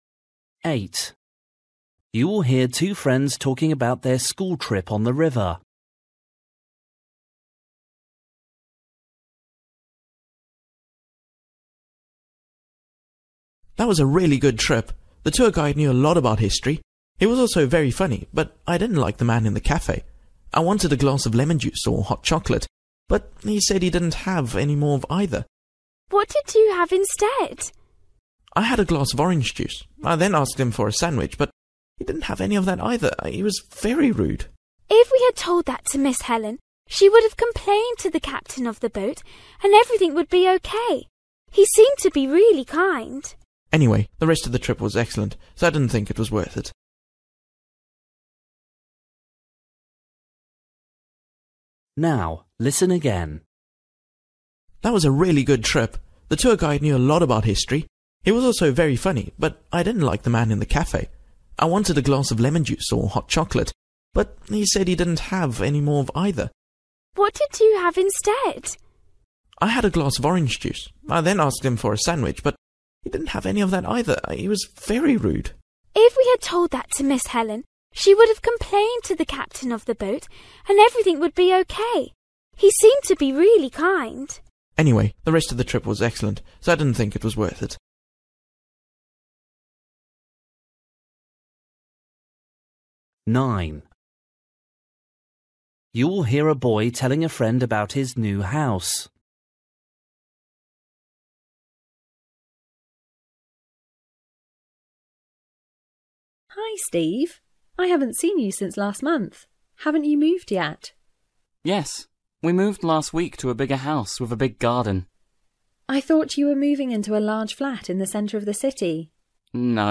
Listening: everyday short conversations
8   You will hear two friends talking about their school trip on the river. What problem did the boy have on the boat?
9   You will hear a boy telling a friend about his new house. What was the main reason the boy’s family moved to the new house?
12   You will hear a girl telling her friend about her summer course. The boy suggests that the girl should